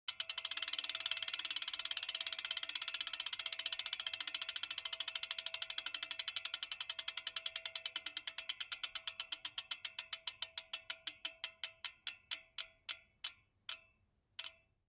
دانلود آهنگ دوچرخه 15 از افکت صوتی حمل و نقل
جلوه های صوتی
دانلود صدای دوچرخه 15 از ساعد نیوز با لینک مستقیم و کیفیت بالا